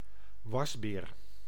Ääntäminen
IPA: /ˈʋɑsˌbeːr/ IPA: [ˈʋɑzbɪːr]